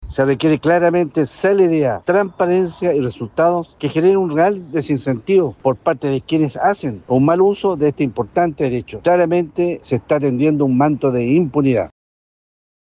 Asimismo, su par de la UDI, Daniel Lilayu, quien expresó su inquietud ante la falta de claridad respecto de los criterios que se están aplicando en el desarrollo de los sumarios.